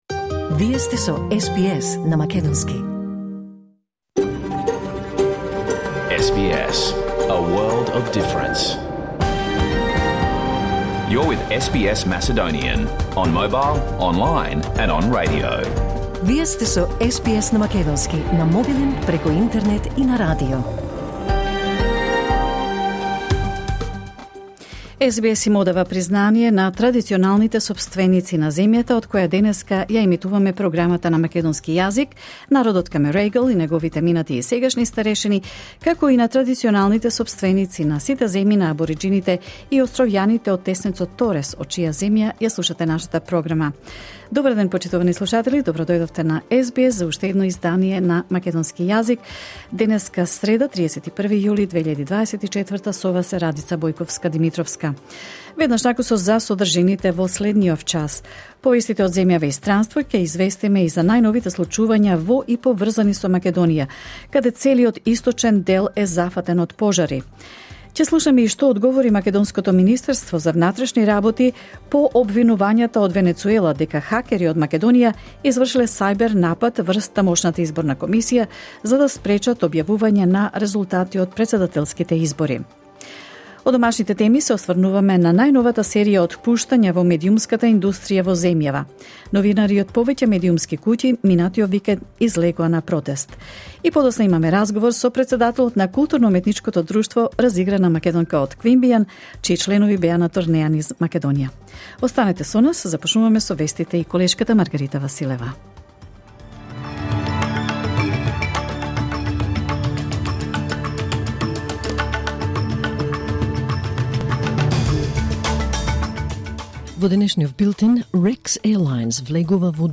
Програмата на СБС на македонски емитувана во живо на 31 јули 2024
SBS Macedonian Program Live on Air 31 July 2024